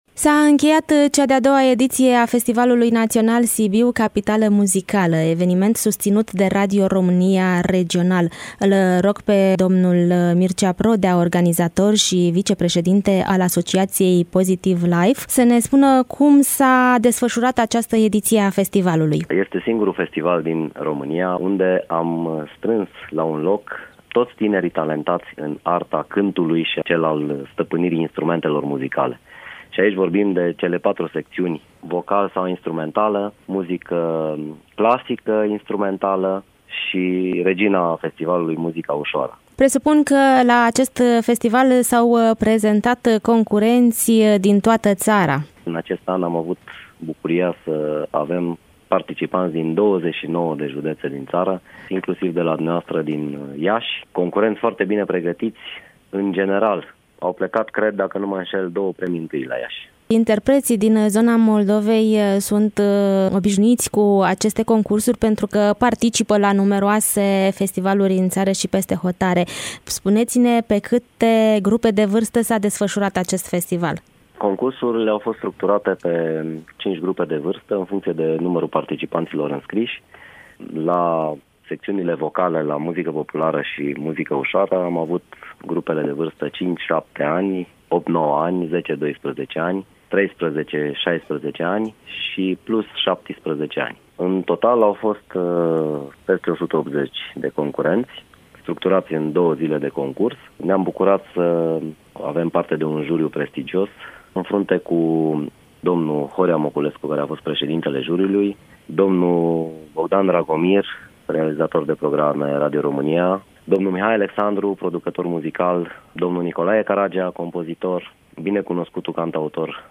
Interviu-Festivalul-National-Sibiu-Capitala-Muzicala.mp3